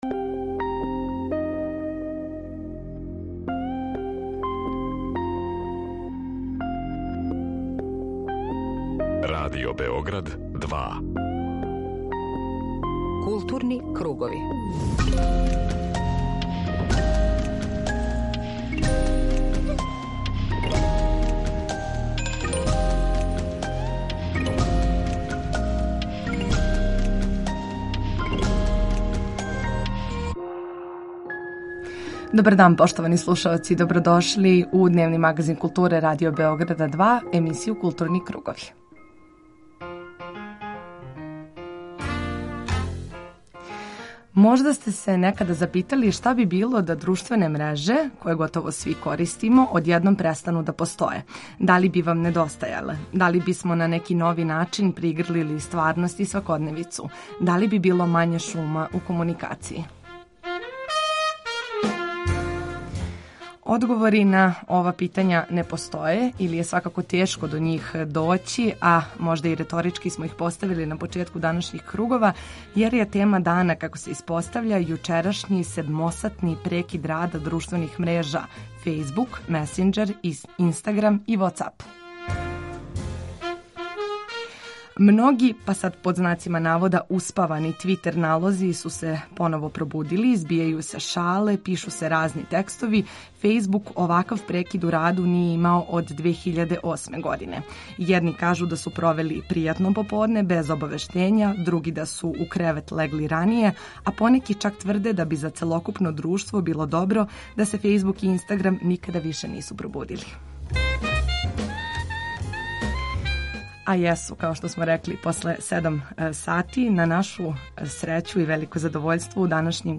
У емисији Културни кругови најавићемо концерт „Жељко Лучић и пријатељи", који ће бити одржан у Опери и театру Мадлениjанум. Чућемо главну звезду ‒ нашег истакнутог оперског уметника Жељка Лучића.
У Београду се отвара Сквер Милене и Гаге, те ћемо чути и делове атмосфере са Врачара.